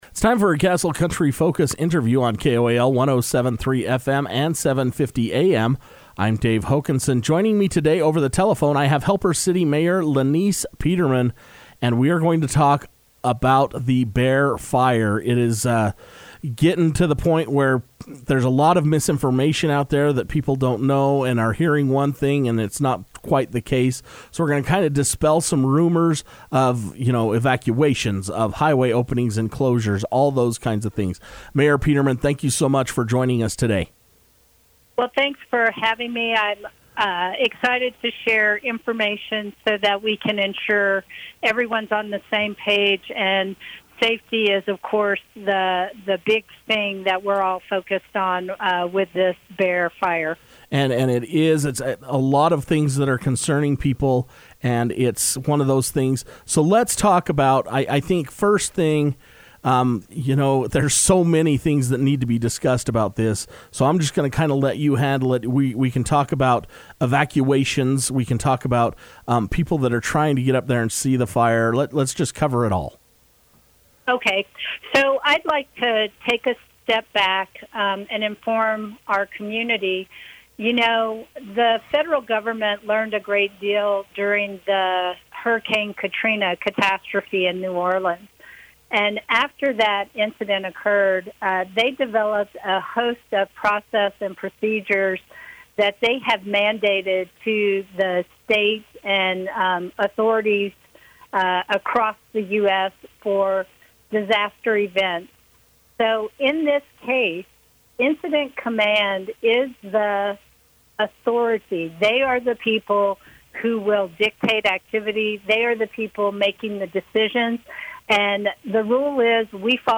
We talked with Helper Mayor Peterman about different reports that have been released and what we can do as citizens to help with the efforts.